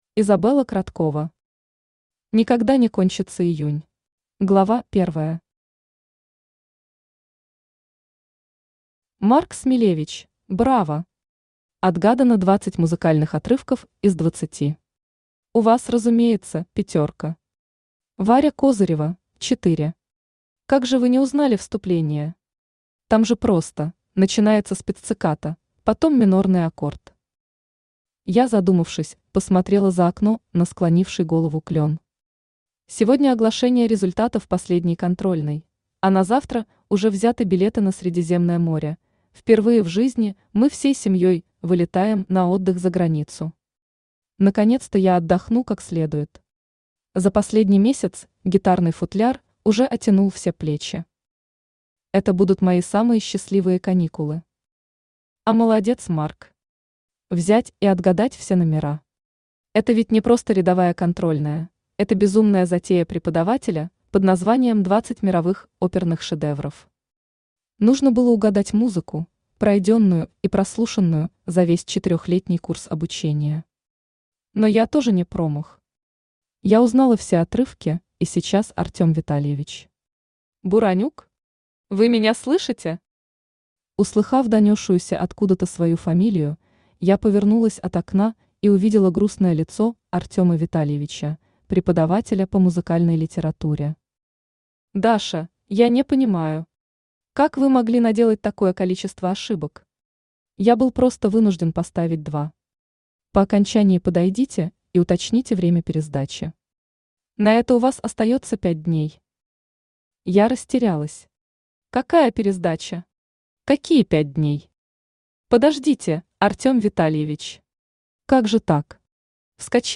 Аудиокнига Никогда не кончится июнь | Библиотека аудиокниг
Aудиокнига Никогда не кончится июнь Автор Изабелла Кроткова Читает аудиокнигу Авточтец ЛитРес.